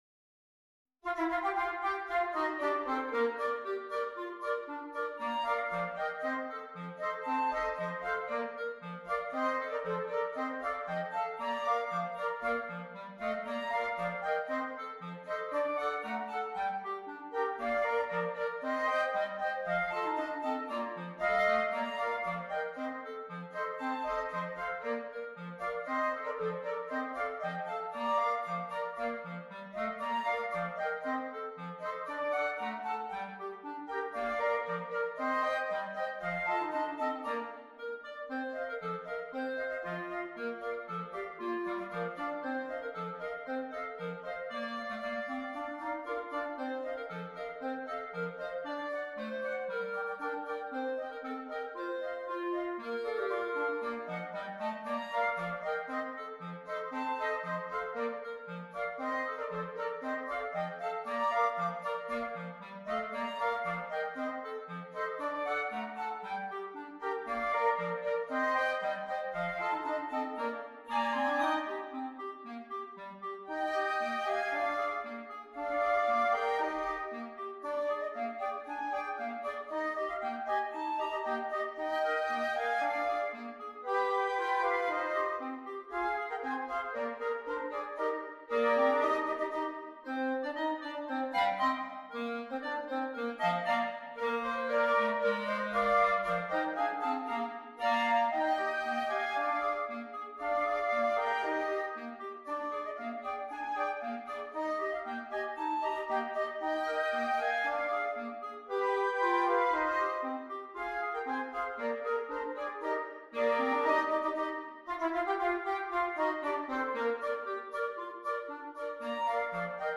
Voicing: Woodwind Quartet